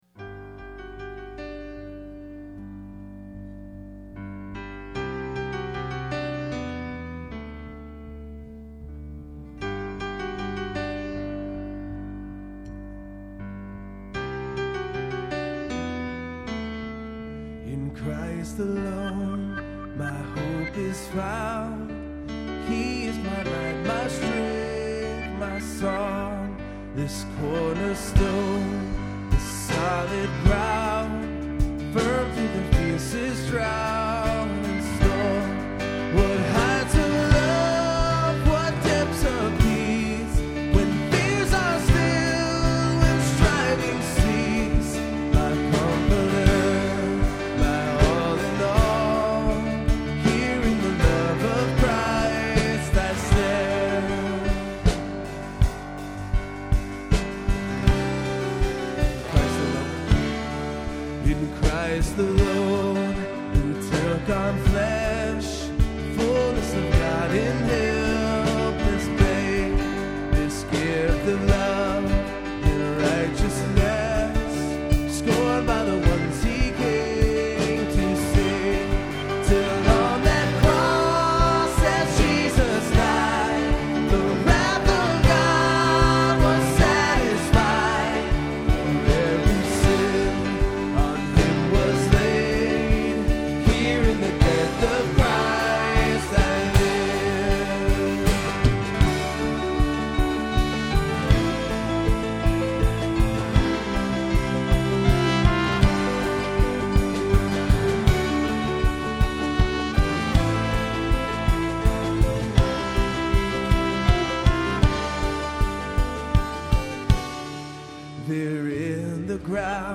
Performed live on Easter at Terra Nova - Troy on 4/12/09.